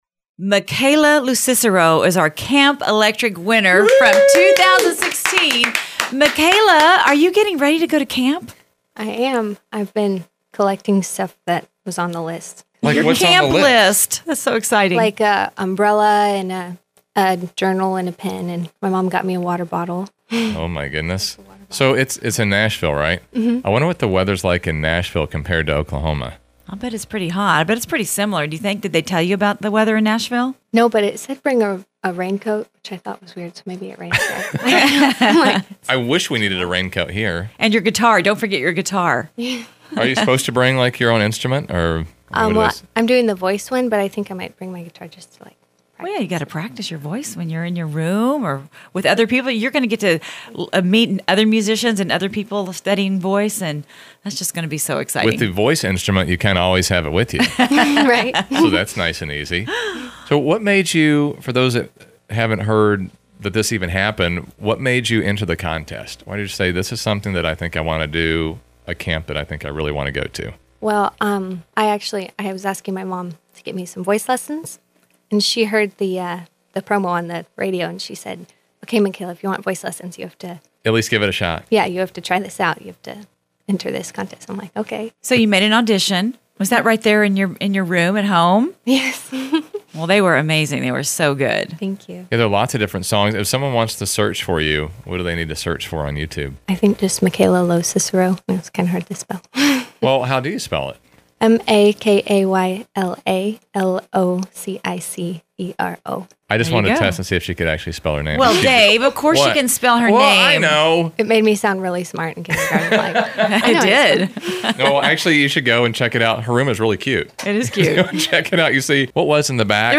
Hear the whole interview along with 2 songs she performed live in the studio!